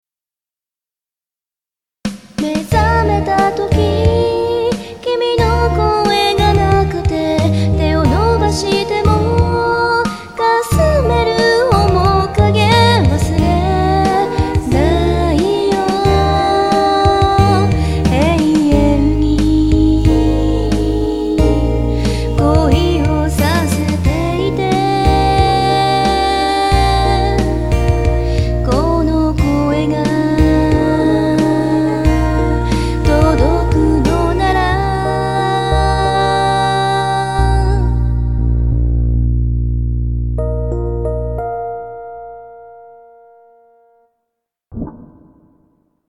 伸ばす部分（恋をさせてい←特にこの部分）がものすごく気に入ってます。